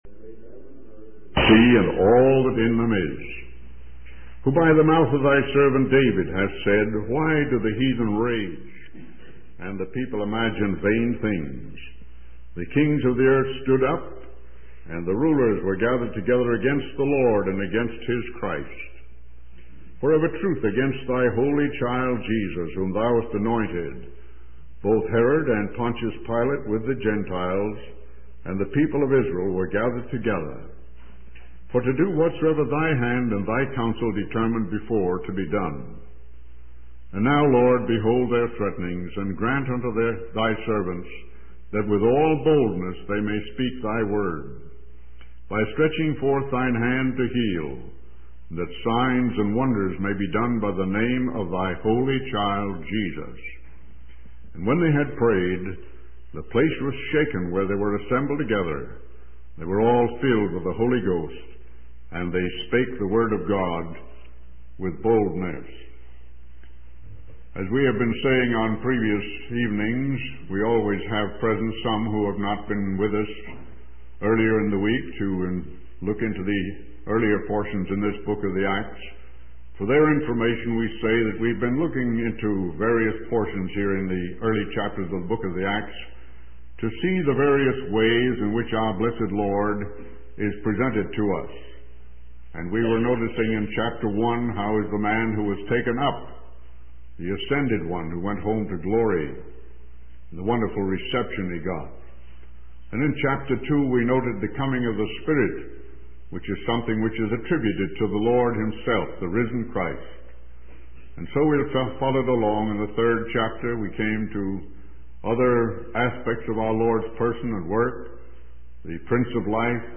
In this sermon, the speaker begins by acknowledging that he is not trying to teach older individuals, but rather provide guidance for those who are new to studying the Word of God.